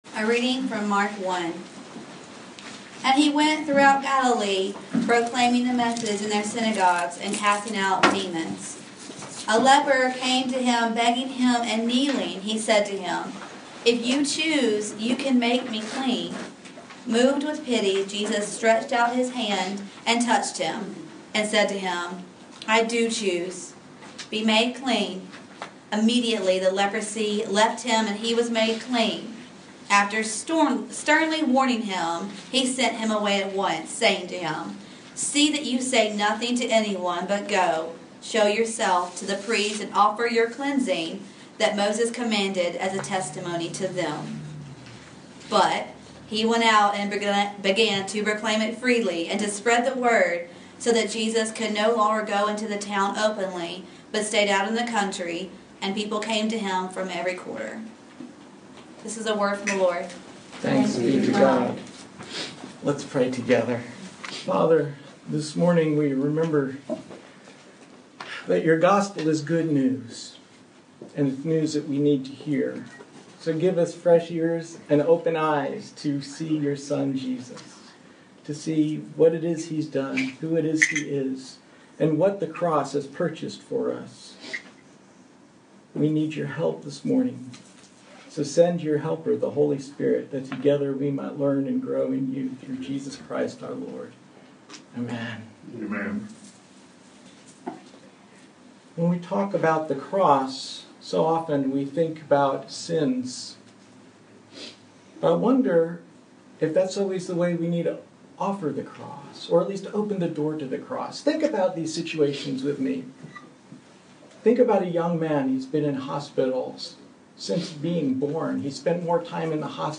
Passage: Mark 1:39-45 Service Type: Sunday Morning